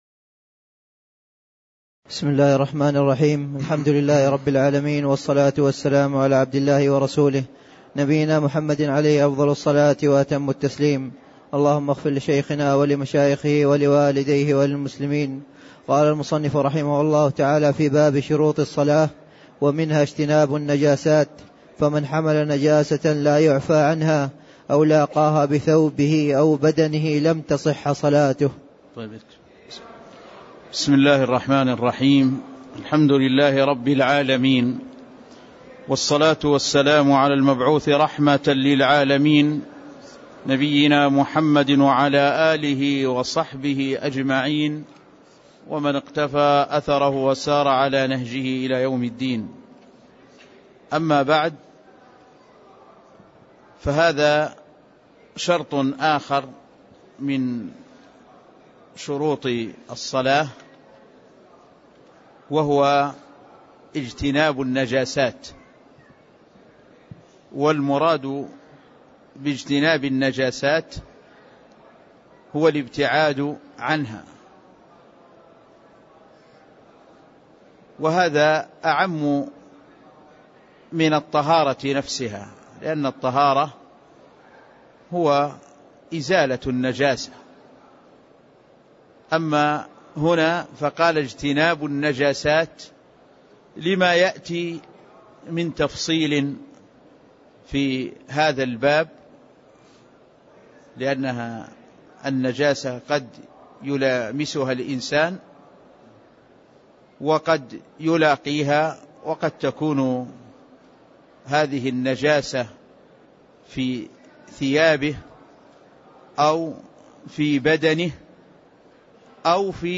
تاريخ النشر ١٩ رجب ١٤٣٥ هـ المكان: المسجد النبوي الشيخ